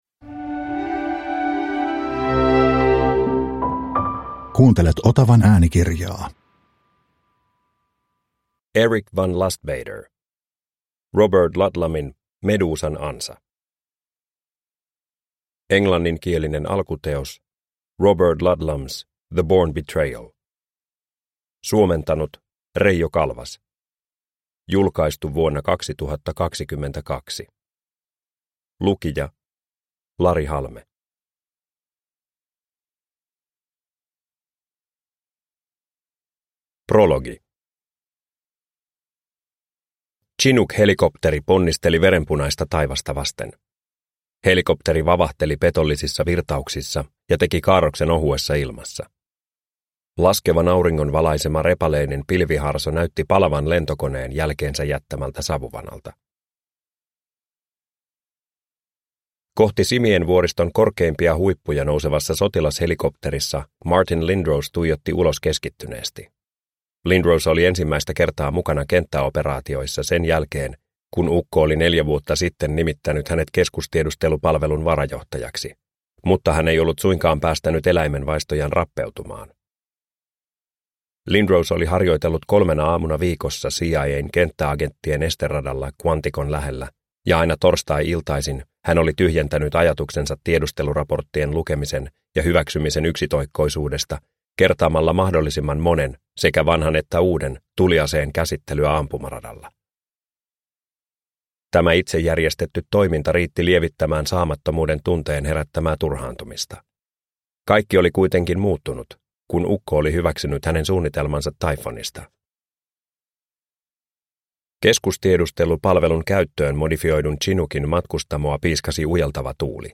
Robert Ludlumin Medusan ansa – Ljudbok – Laddas ner